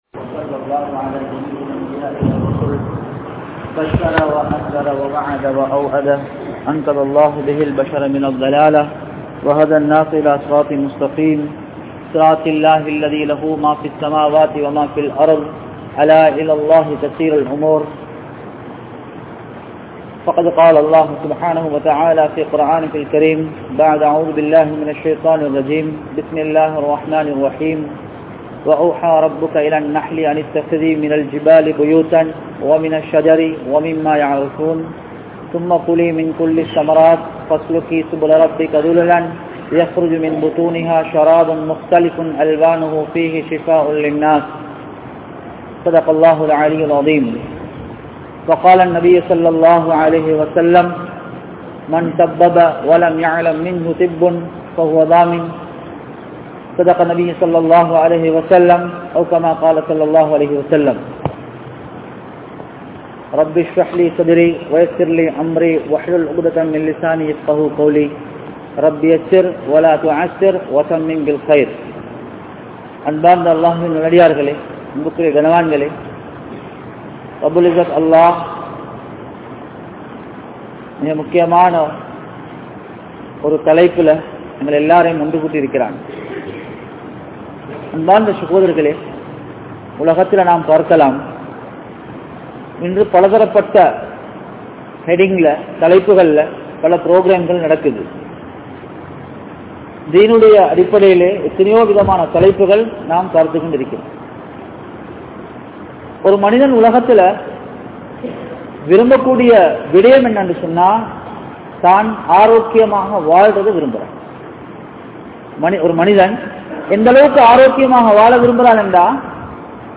Islam Koorum Maruthuvam (இஸ்லாம் கூறும் மருத்துவம்) | Audio Bayans | All Ceylon Muslim Youth Community | Addalaichenai